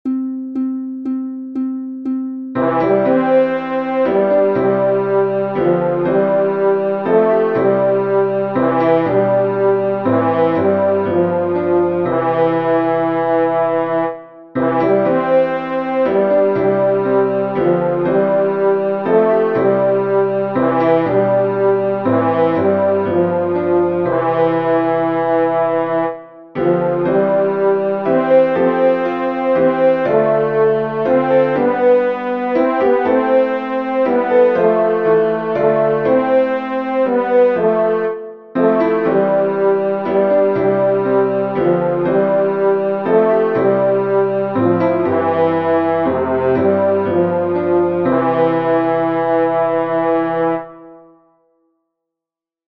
You will hear five beats before your entrance.
The sound of those beats is a C. For everyone except the Tenors, that’s your note. The tenor note is E, so you will have to think up one-third.
resignation-with-countin-tenor-1.mp3